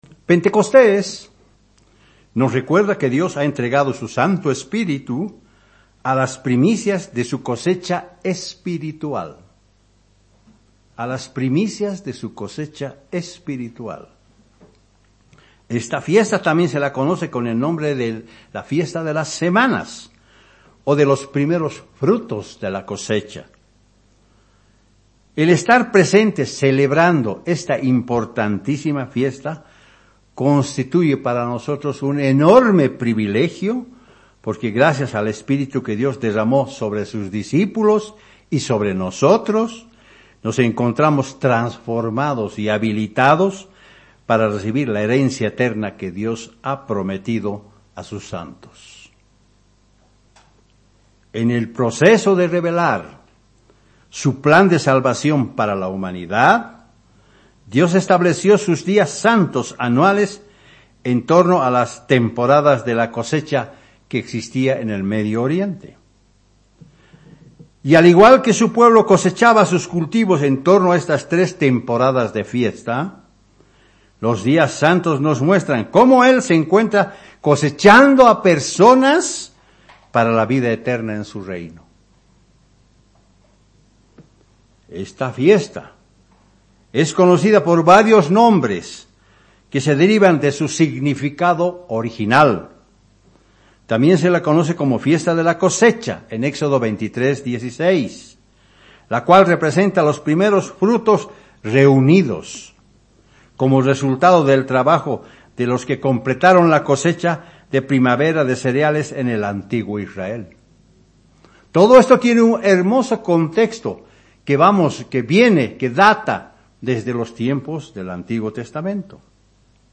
Sermones
Given in La Paz